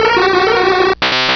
Cri de Soporifik dans Pokémon Rubis et Saphir.
Cri_0096_RS.ogg